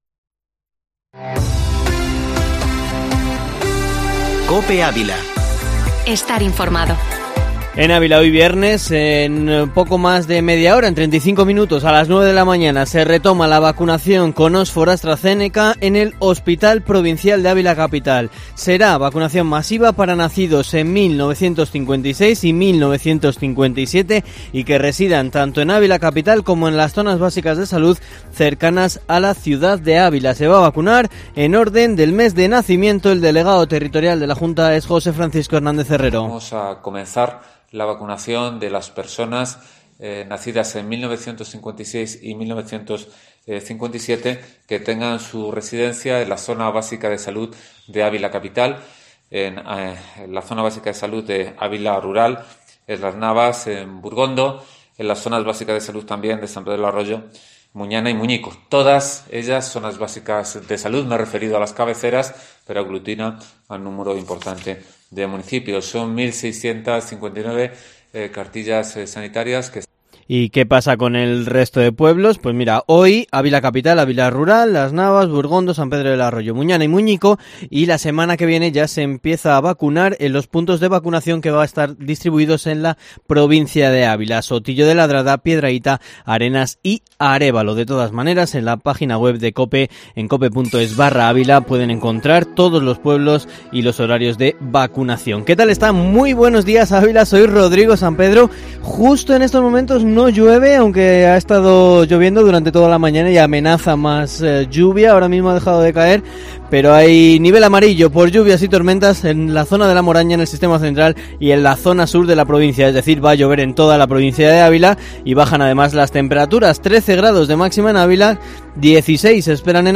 Informativo matinal Herrera en COPE Ávila 09/04/2021